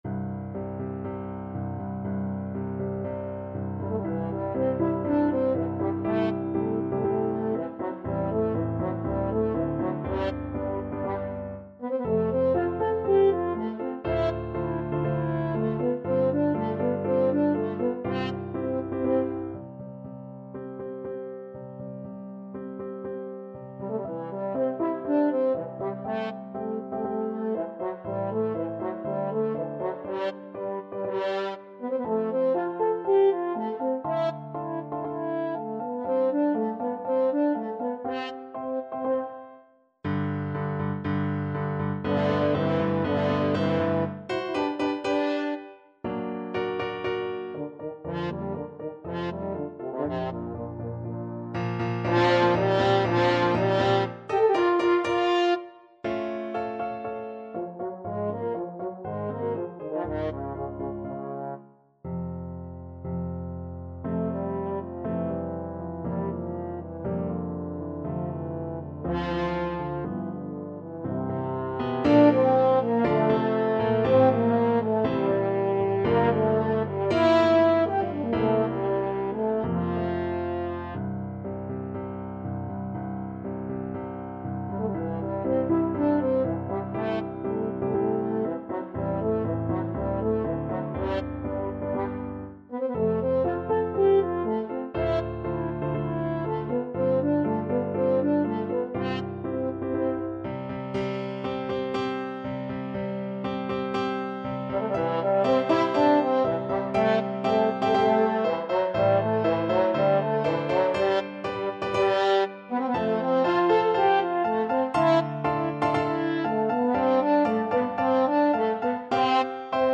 Free Sheet music for French Horn
French Horn
C major (Sounding Pitch) G major (French Horn in F) (View more C major Music for French Horn )
~ = 120 Tempo di Marcia un poco vivace
4/4 (View more 4/4 Music)
Classical (View more Classical French Horn Music)